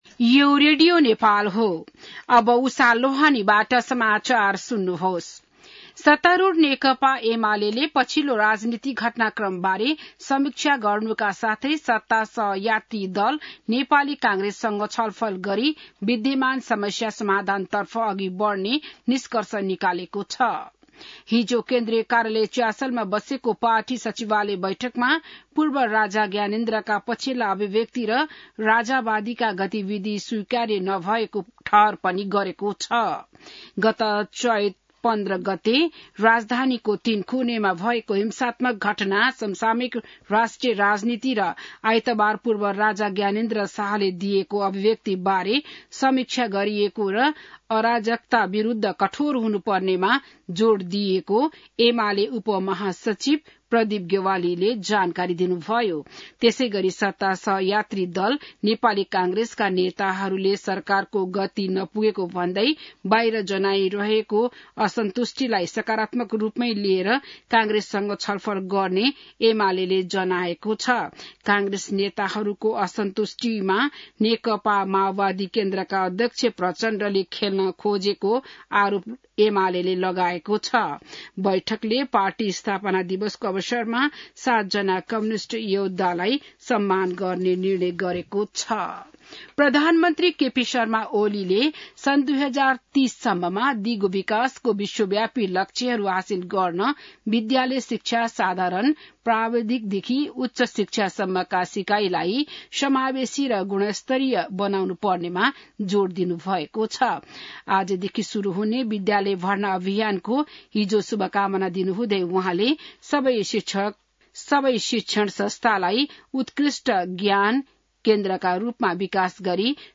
बिहान १० बजेको नेपाली समाचार : २ वैशाख , २०८२